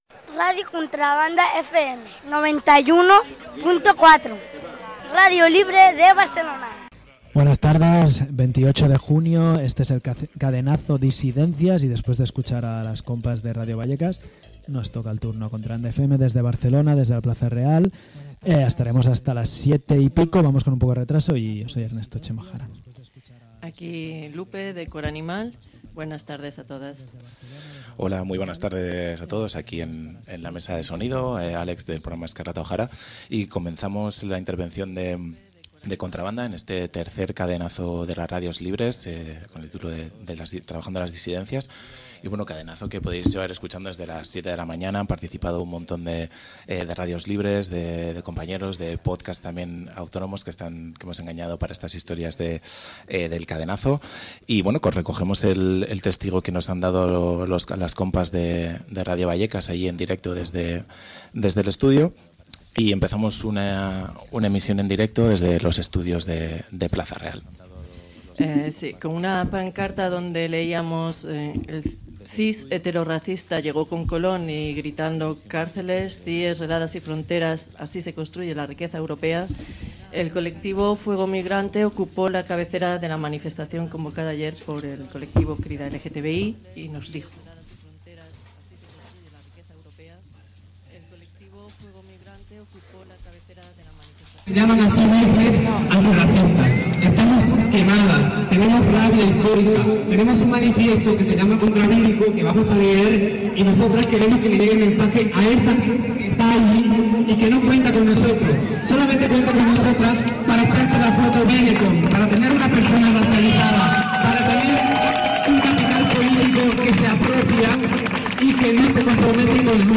Coincidiendo con el Día del Orgullo LGTBI+ el domingo 28 de junio, más de 20 radios libres y podcast de todo el estado se unieron en una emisión conjunta dedicada a las luchas y disidencias en la nueva anormalidad.